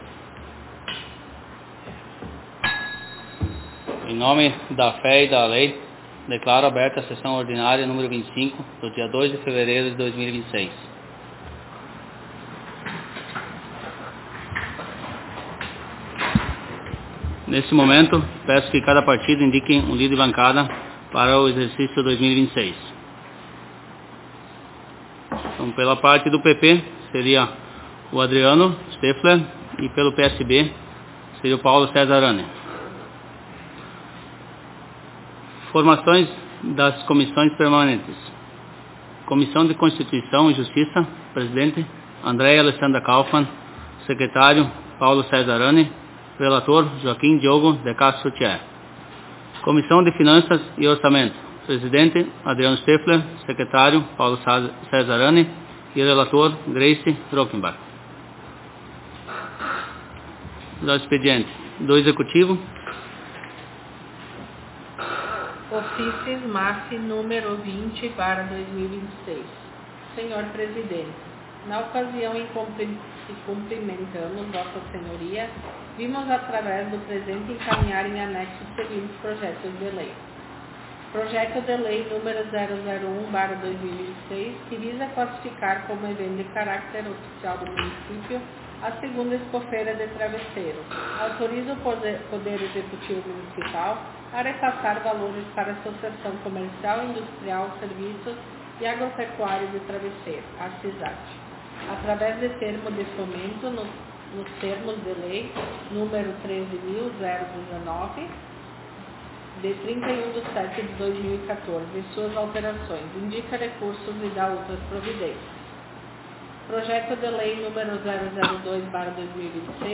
Aos 02 (dois) dias do mês de fevereiro do ano de 2026 (dois mil e vinte e seis), na Sala de Sessões da Câmara Municipal de Vereadores de Travesseiro/RS, realizou-se a Vigésima Quinta Sessão Ordinária da Legislatura 2025-2028.